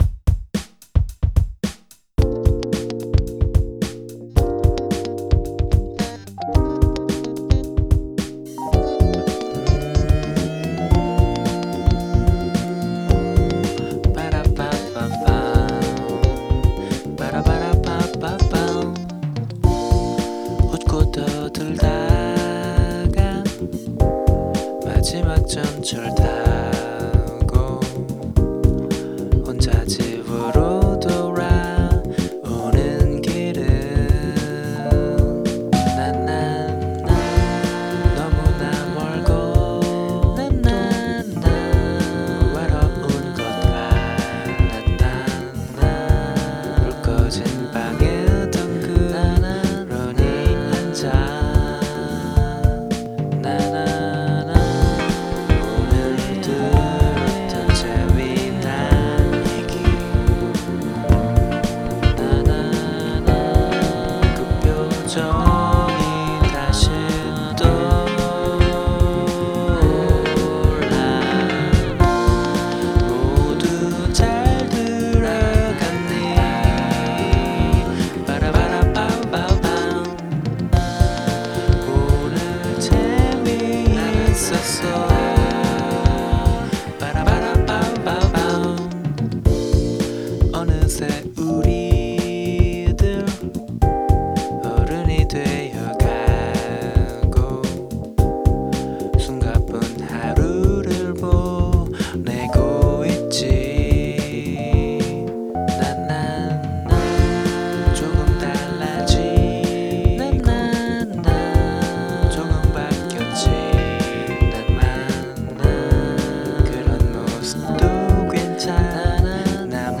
곡 하나 하나에 두 가지 이상의 쟝르적 속성이 섞이도록 음악적 컨셉트를 설정했습니다.
예컨데, 레게이면서 포크 같기도 하고, 훵키인데 동시에 라틴 같기도 한 그런.